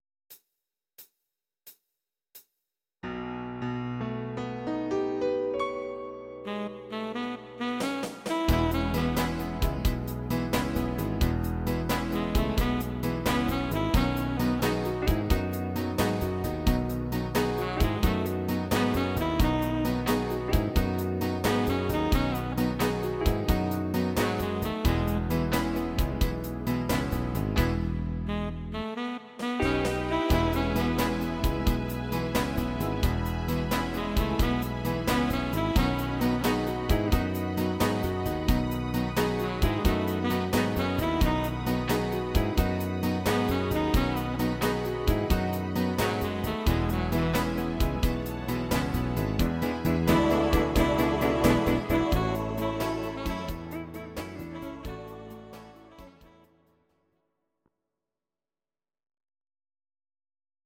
Audio Recordings based on Midi-files
Oldies, Country, 1950s